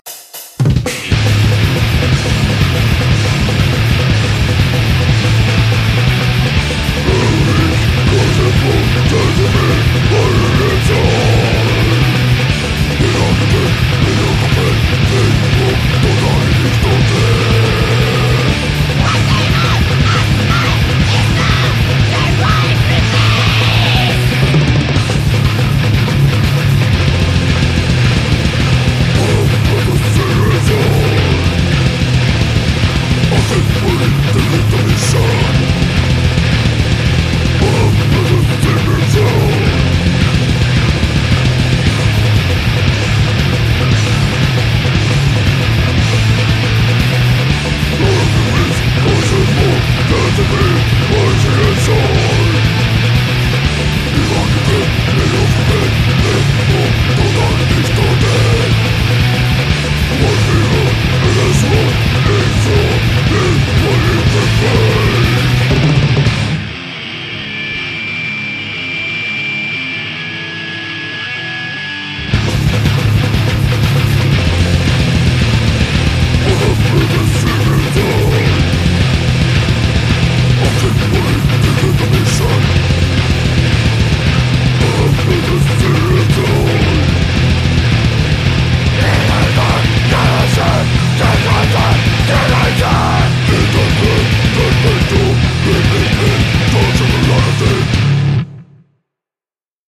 ULEÅBORG HARDCORE